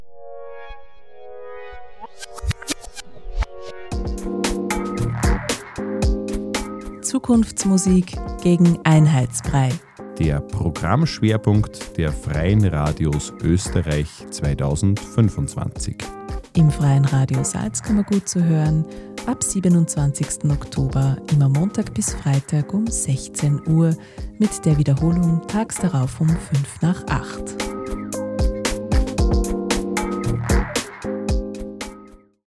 Sendungstrailer